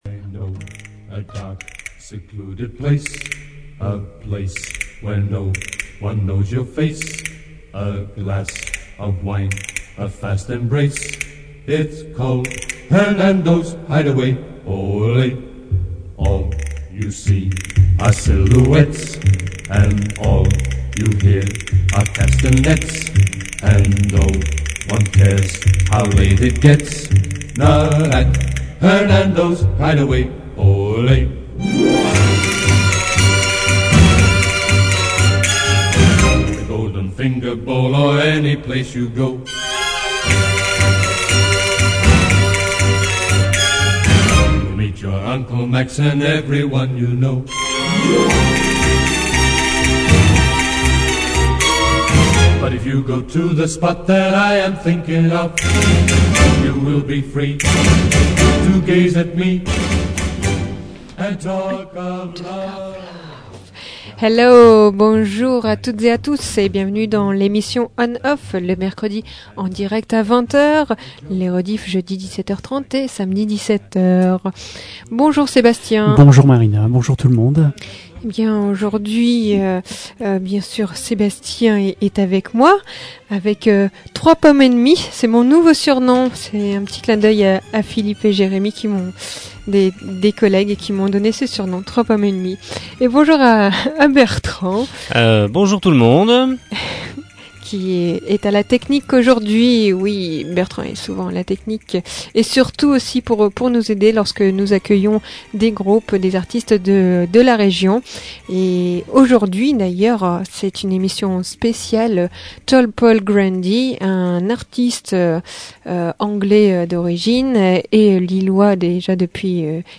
ONOFF a donc voulu en savoir un peu plus et vous faire partager un petit moment de bonheur musical en direct.